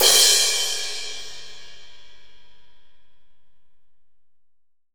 Index of /90_sSampleCDs/AKAI S6000 CD-ROM - Volume 3/Crash_Cymbal1/15-18_INCH_AMB_CRASH
16AMB CRS1-S.WAV